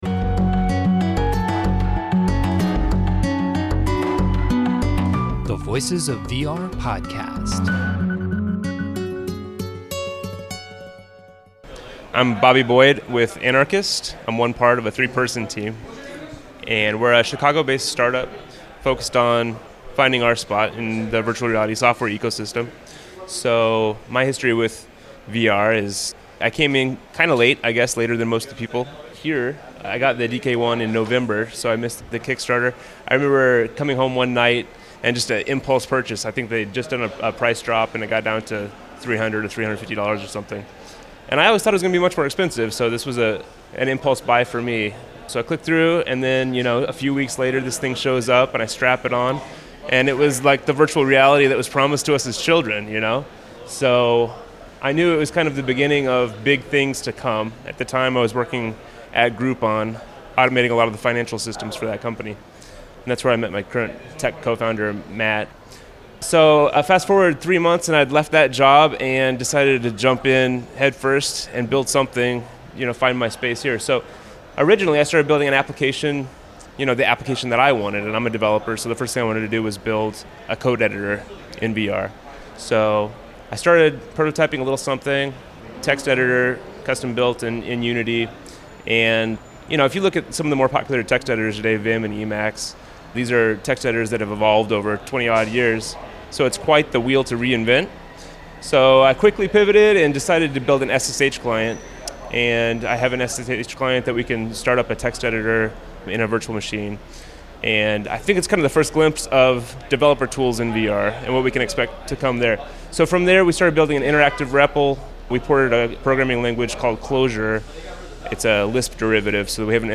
This interview was recorded at last year’s Oculus Connect conference.